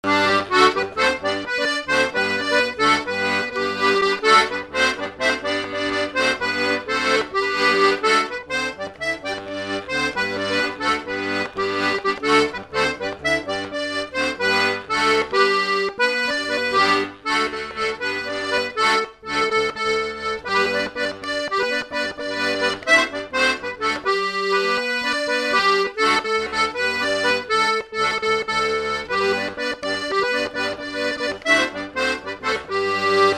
Chants brefs - A danser
danse : polka des bébés ou badoise ; danse : polka ;
Enquête Arexcpo en Vendée
Pièce musicale inédite